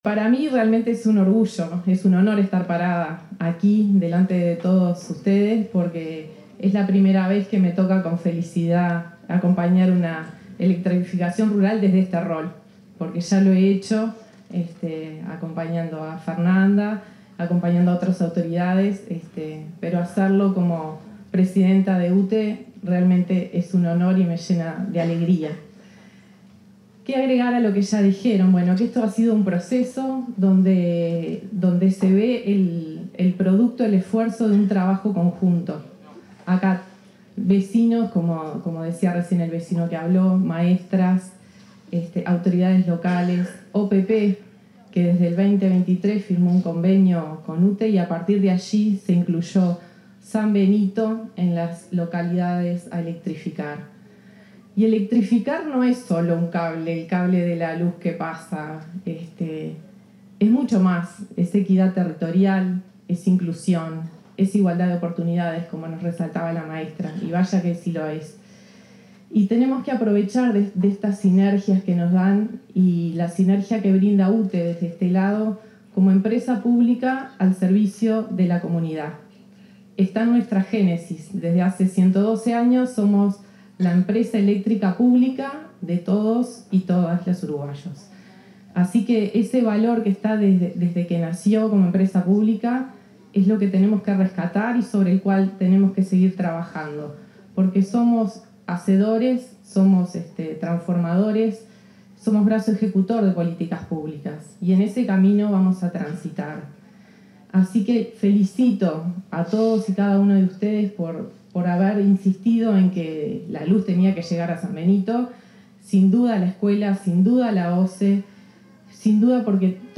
Palabras de autoridades en inauguración de obras de electrificación rural en Tacuarembó
La presidenta de UTE, Andrea Cabrera, y la ministra de Industria, Energía y Minería, Fernanda Cardona, se expresaron en el acto de presentación de las
Discursos.mp3